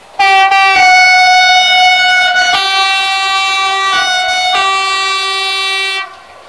X 72500   Exemple de sifflet
Lecture : cliquez sur le panneau "S" (sifflet). 141 Ko